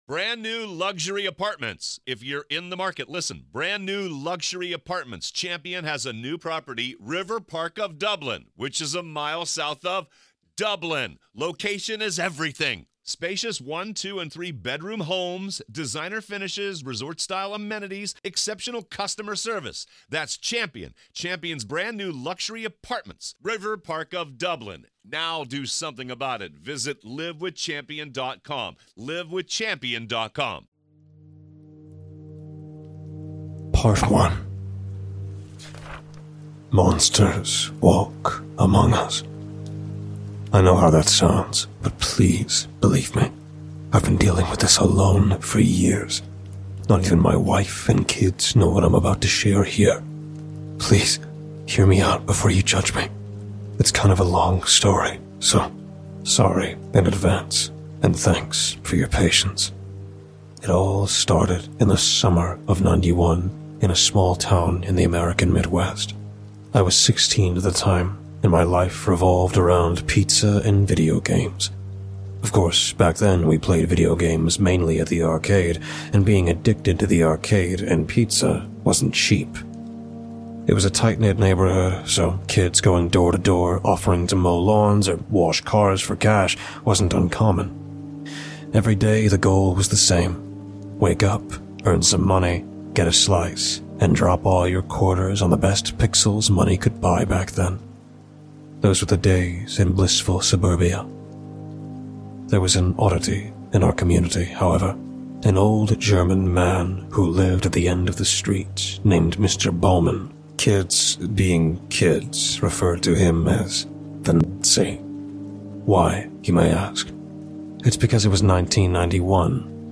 Story